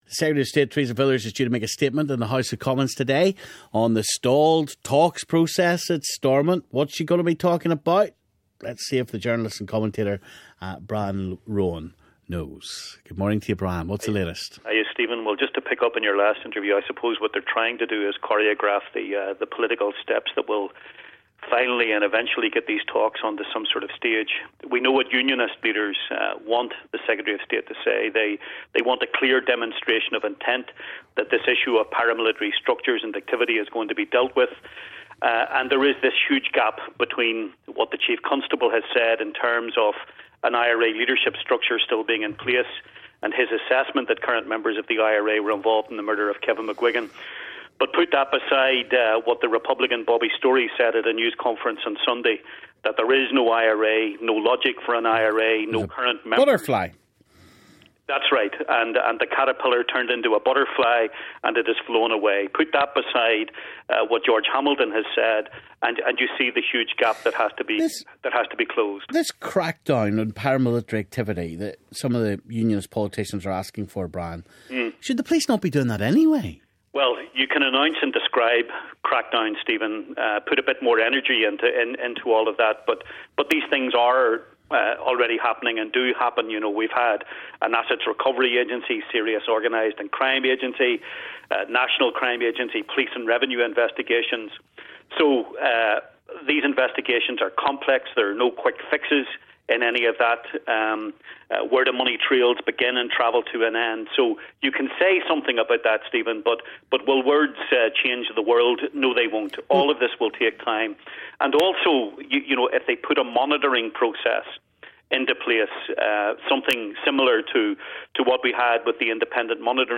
Stephen talks to journalist and commentator